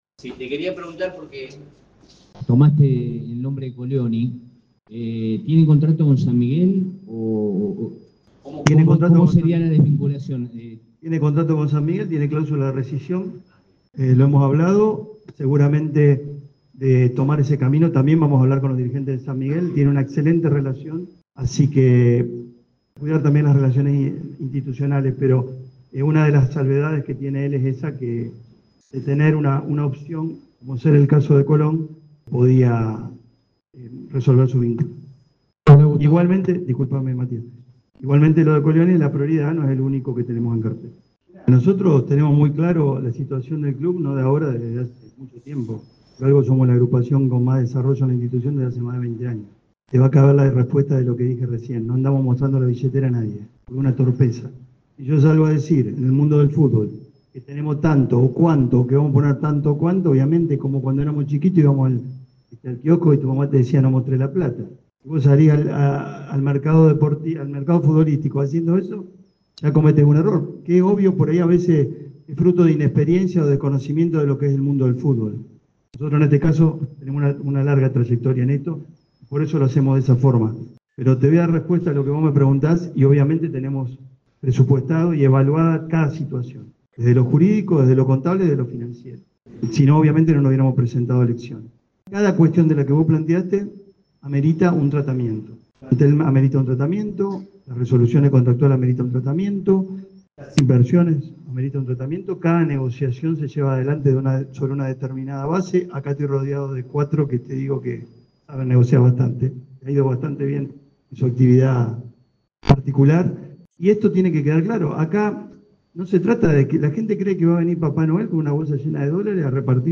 Uno de los primeros temas que tocó el experimentado candidato rojinegro en la charla con la EME Deportivo fue el ansiado ascenso.